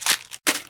trashcan1.ogg